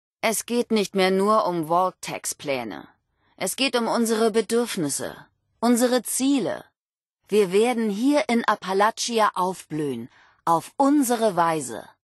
Vault-76-Aufseherin_00402a96_2.ogg (OGG-Mediendatei, Dateigröße: 91 KB.
Wastelanders: Audiodialoge